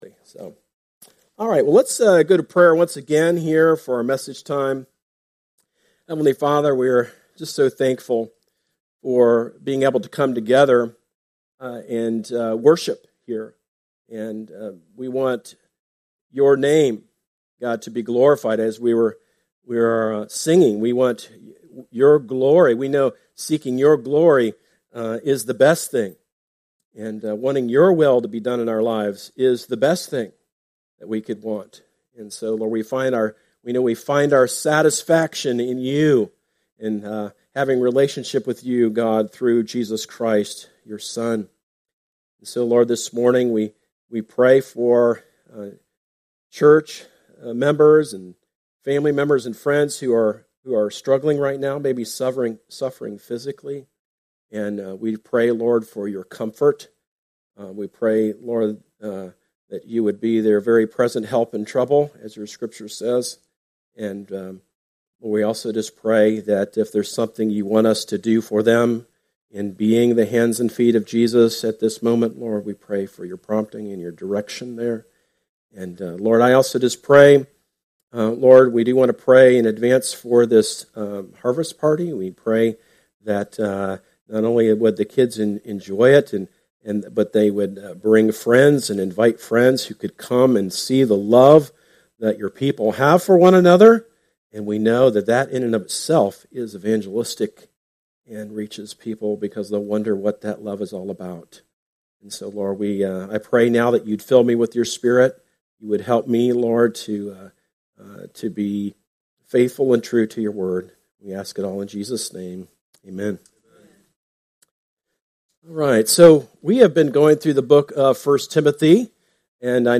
Sermons – Darby Creek Church – Galloway, OH